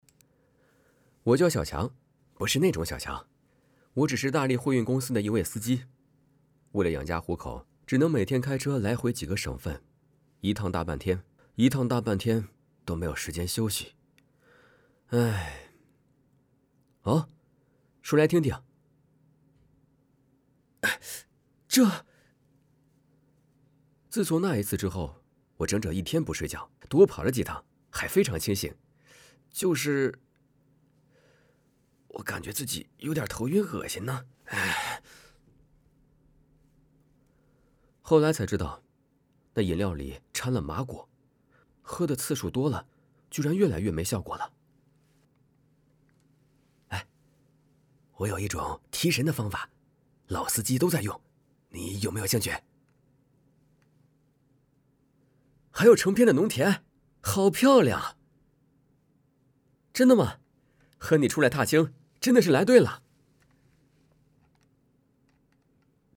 病毒配音